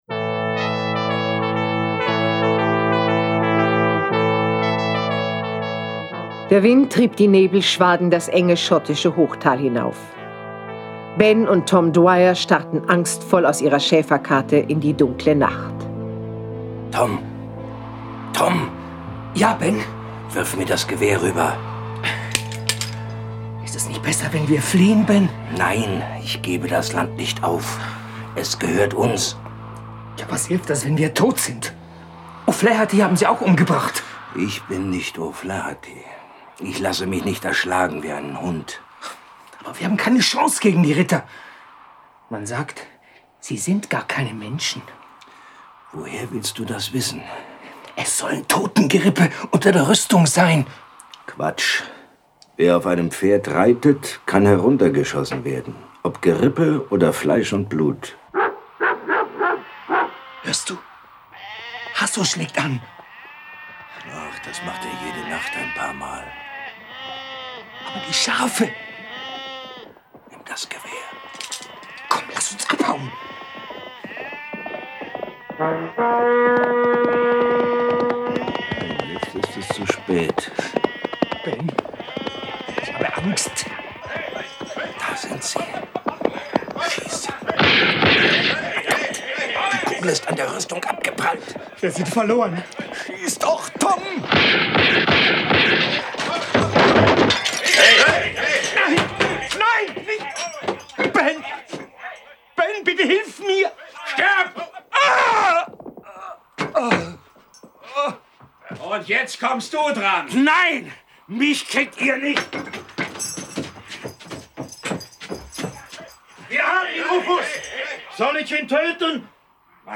Jason Dark (Autor) diverse (Sprecher) Audio-CD 2017 | 1.